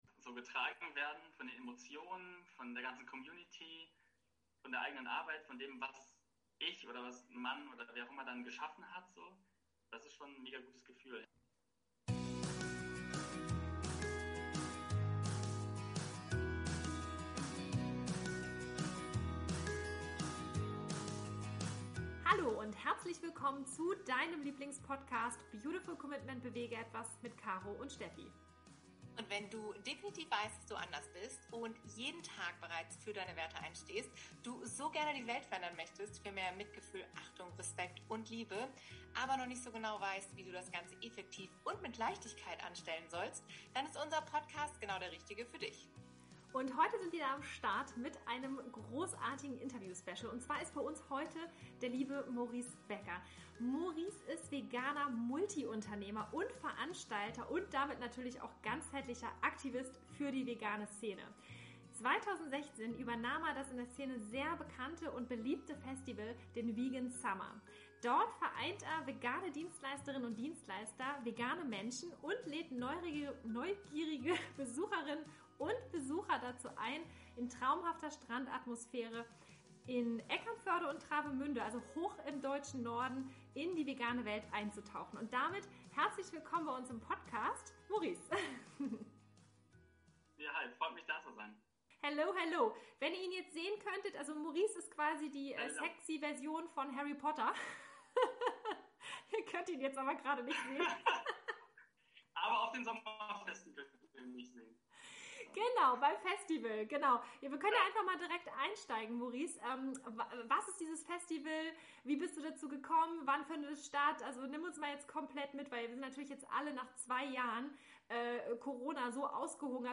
In diesem inspirierenden Interview erfährst du, wie auch du Teil dieser wundervollen Vegan Summer-Community werden kannst.